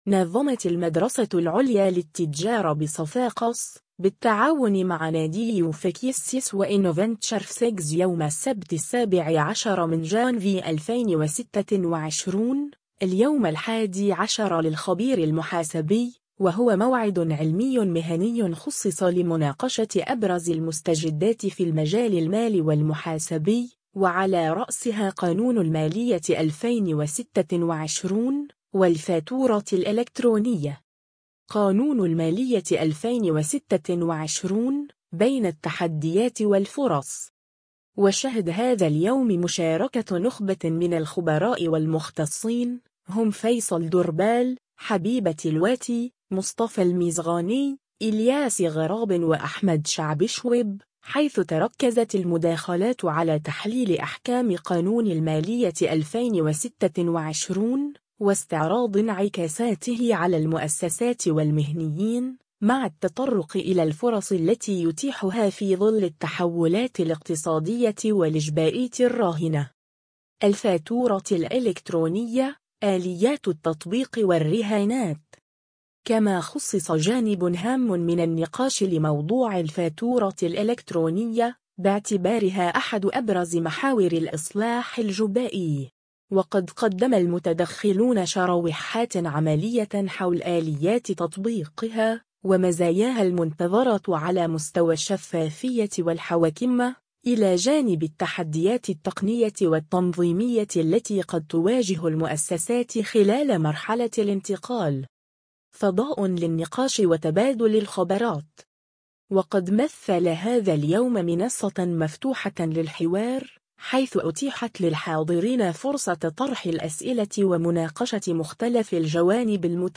نظّمت المدرسة العليا للتجارة بصفاقس، بالتعاون مع ناديي UFEC-ESCS وInoventure-FSEGS، يوم السبت 17 جانفي 2026، اليوم الحادي عشر للخبير المحاسبي، وهو موعد علمي مهني خُصّص لمناقشة أبرز المستجدات في المجال المالي والمحاسبي، وعلى رأسها قانون المالية 2026، والفاتورة الإلكترونية.
وقد مثّل هذا اليوم منصة مفتوحة للحوار، حيث أُتيحت للحاضرين فرصة طرح الأسئلة ومناقشة مختلف الجوانب المتعلقة بالمنظومة الجبائية والمحاسبية، بما في ذلك الإيجابيات والسلبيات والانتظارات المرتبطة بالإصلاحات الجديدة.